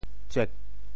棁 部首拼音 部首 木 总笔划 11 部外笔划 7 普通话 zhuō 潮州发音 潮州 zuêg4 文 中文解释 梲 <名> 木杖 [stick] 梲,木杖也。